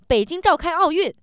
angry